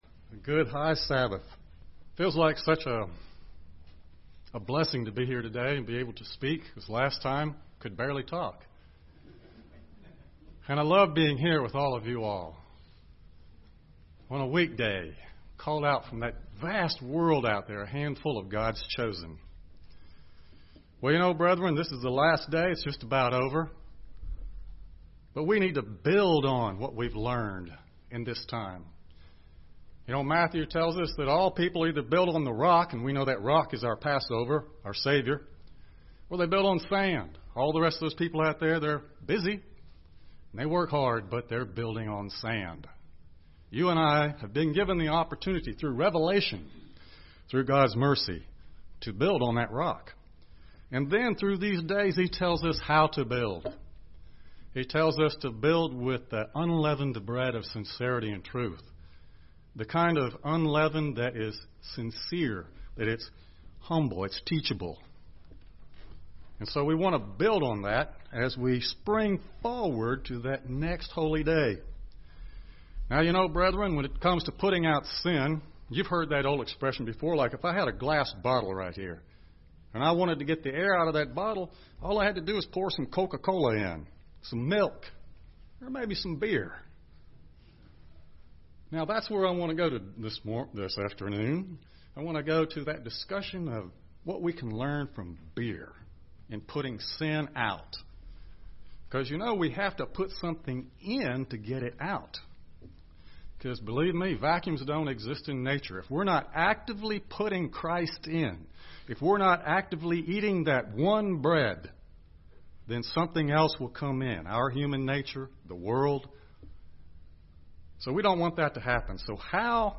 The sermon draws lessons from the steps of brewing beer and making compost. There are many parallels to the transformation Christians experience as we strive to walk the unleavened life.